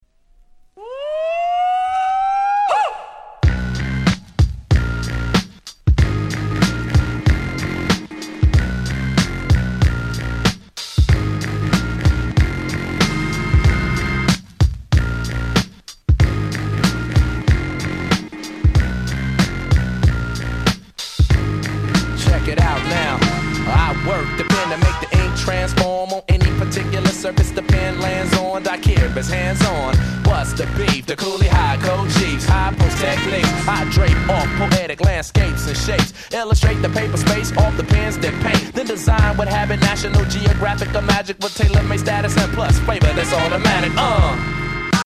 【Media】Vinyl 12'' Single (Promo)
※試聴ファイルは別の盤から録音してございます。
00's Underground Hip Hop Classics !!